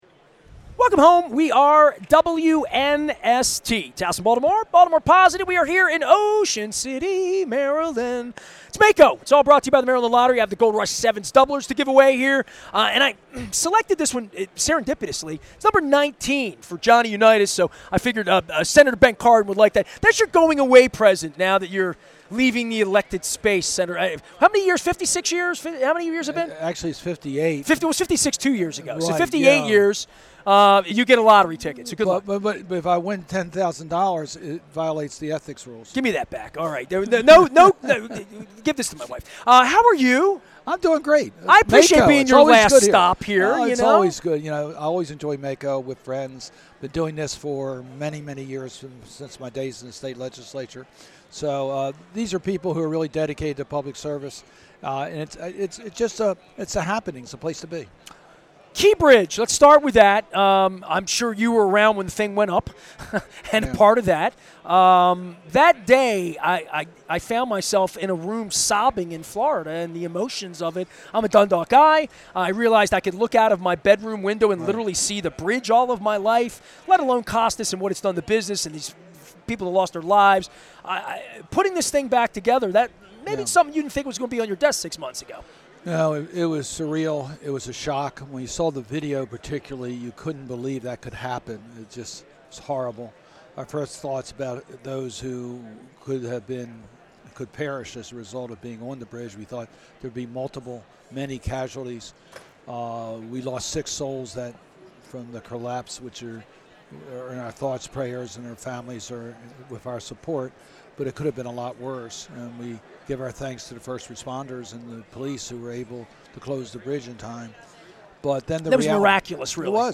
His political legacy as he departs Congress and the need for civility and importance of independent journalism in maintaining a healthy democracy were also on the Weis conversation menu from the beach.